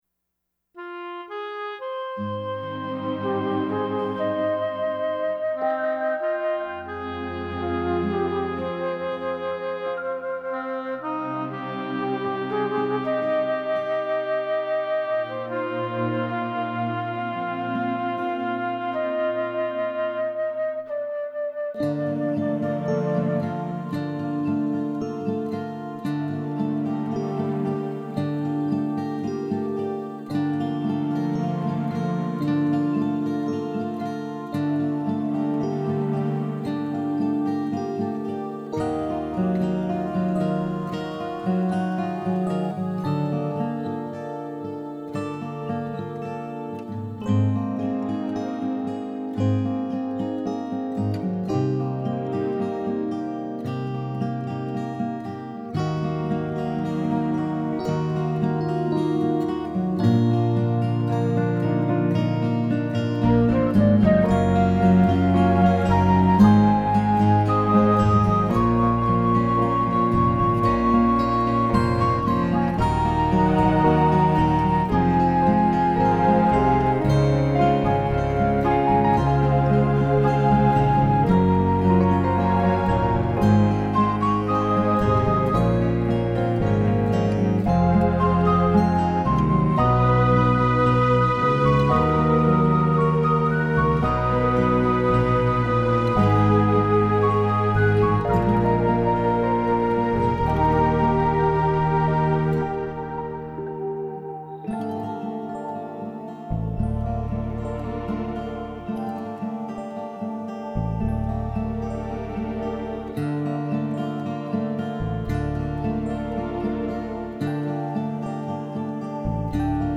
This medley of lush song arrangements convey peacefulness.